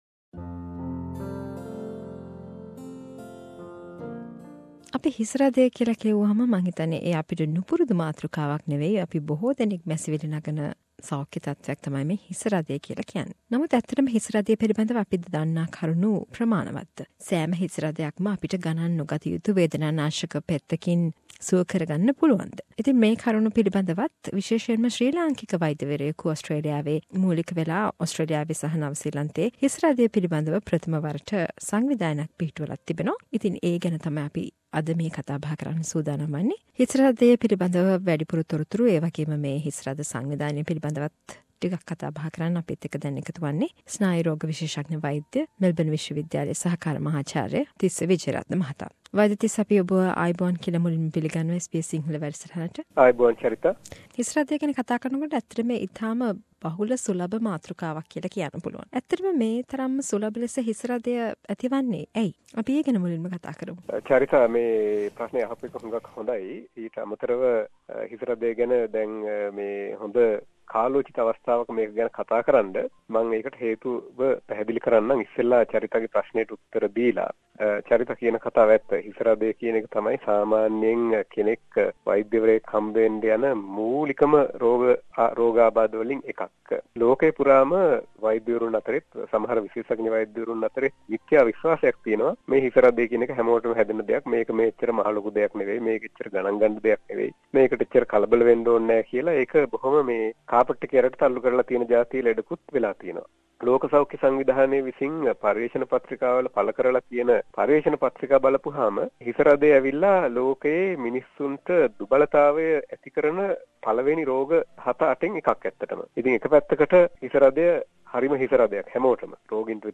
A discussion about headaches and formation of Australia New Zealand Headache society